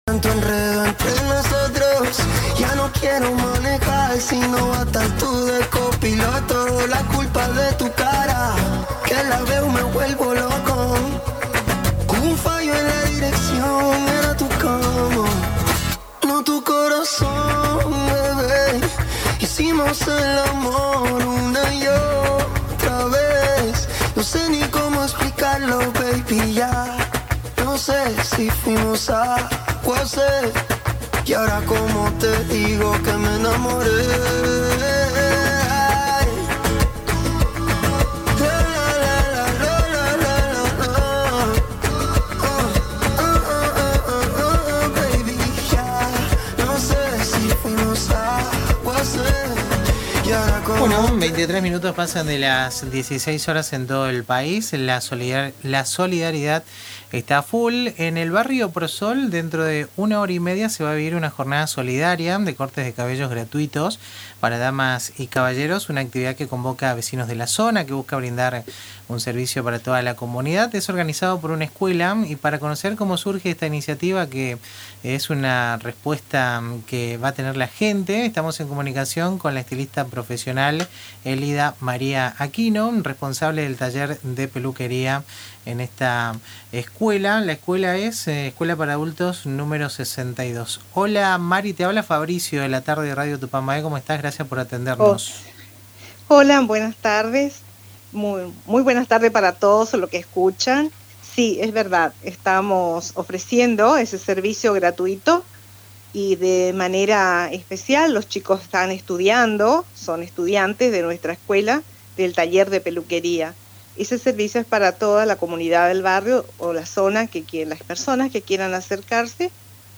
Durante una entrevista radial en el programa El Ritmo Sigue